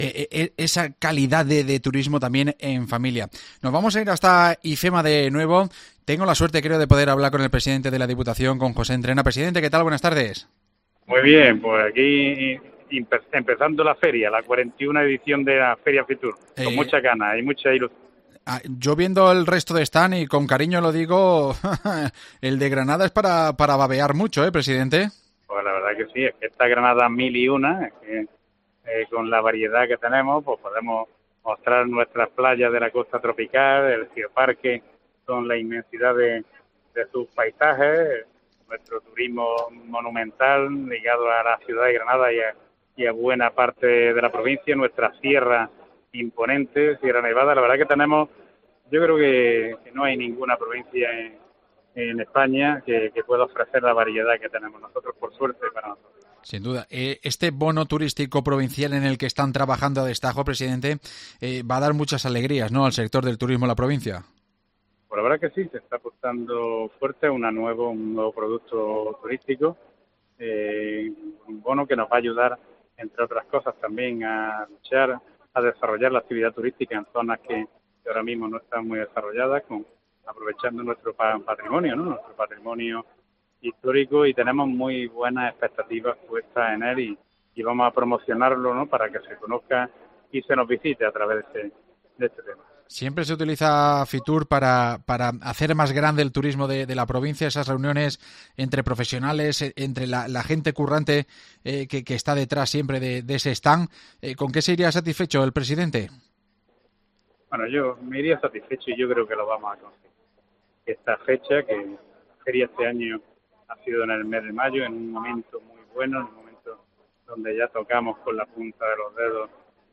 Entrevista a José Entrena, presidente de la Diputación